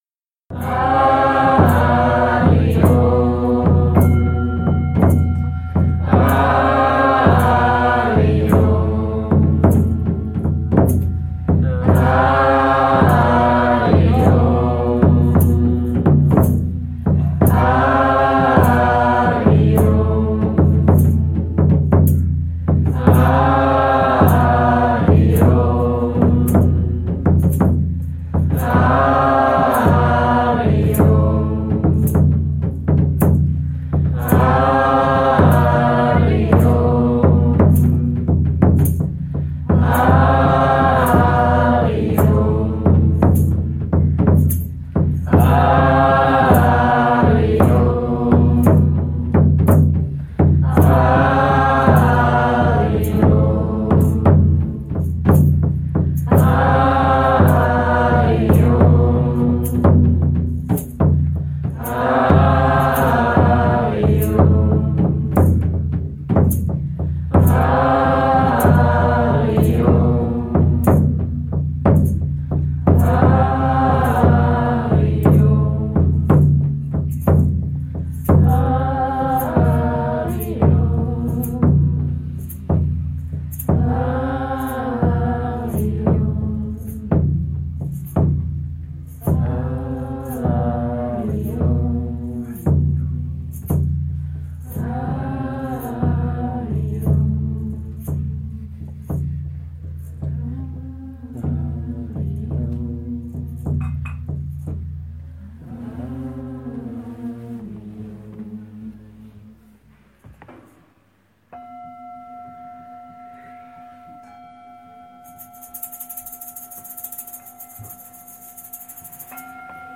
Soirée Chants Sacrés, Mantra et Tambours